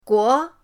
guo2.mp3